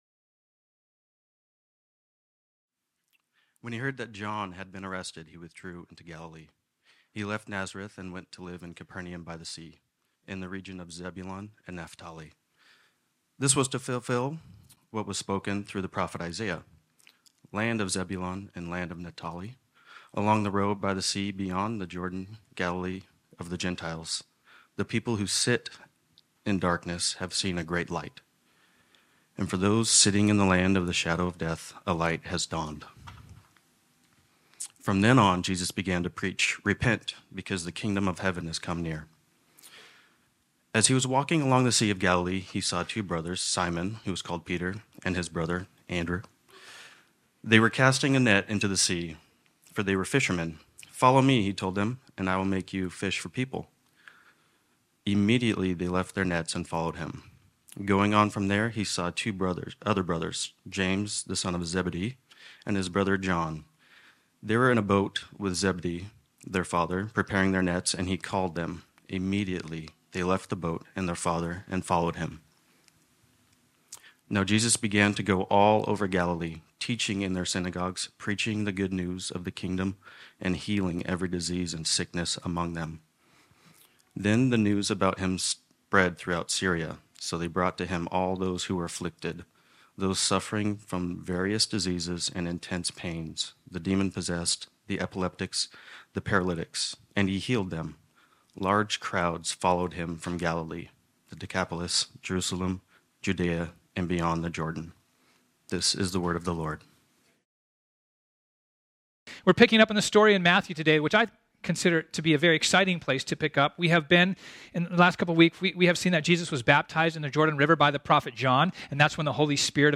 This sermon was originally preached on Sunday, January 21, 2024.